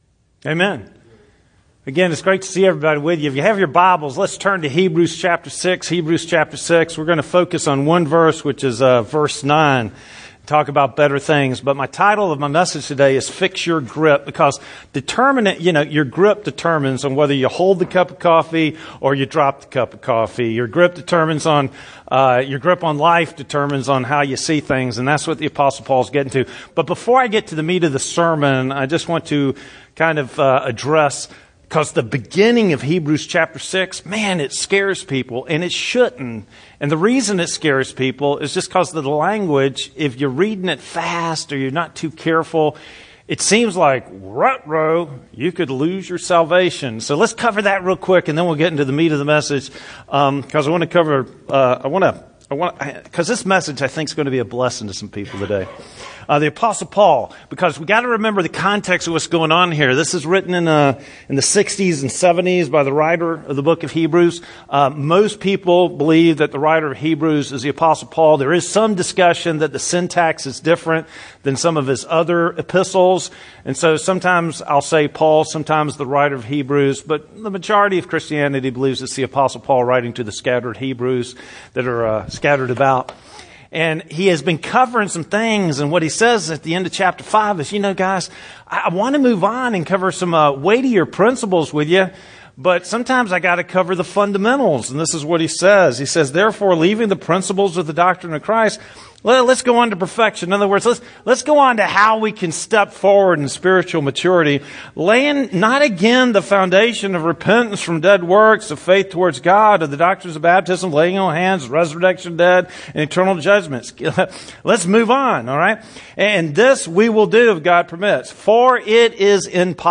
Sermon Video & Audio